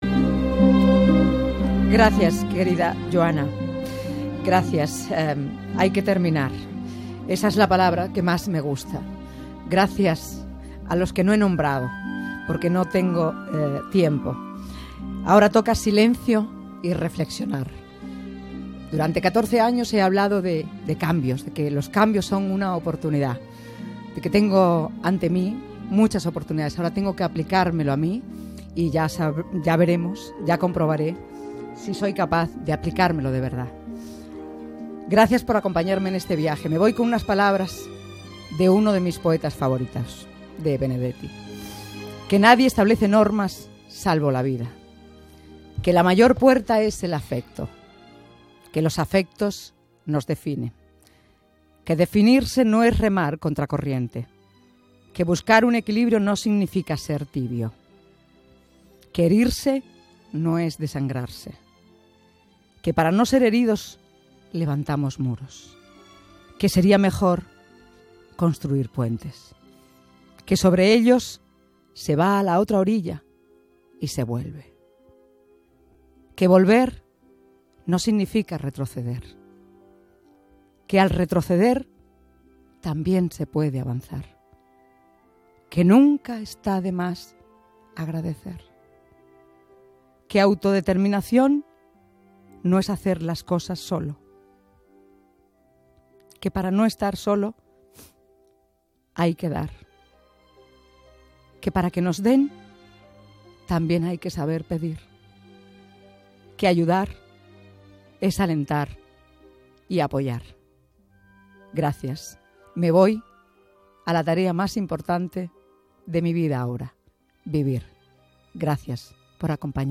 Comiat de la presentadora d'Onda Cero en acabar el programa, agraïment i paraules de Mario Benedetti
Entreteniment
Gemio, Isabel